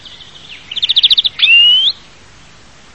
Carduelis chloris
Un chiaro trilli ‘didididi’ e anche un nasale ‘dgiuii’. Il canto è una serie di richiami intervallati a lunghi ‘tsuììììì’.
Verdone_Carduelis_chloris.mp3